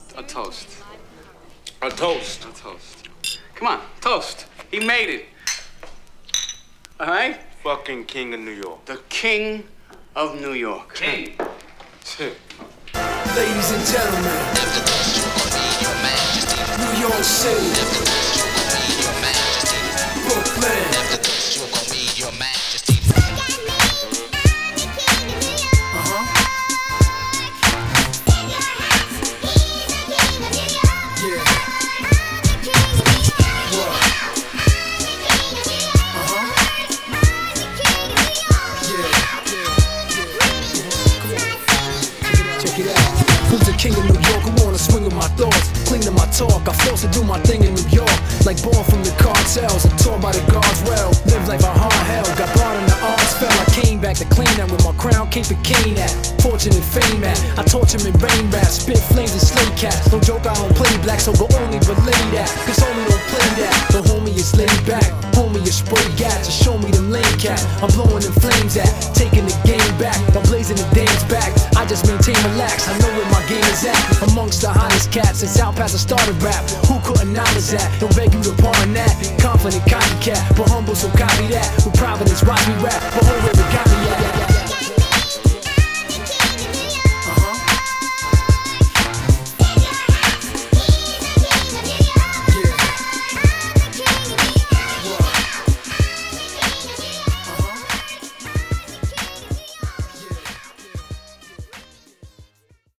2. > HIPHOP
当時流行った45rpmサンプリングを使用！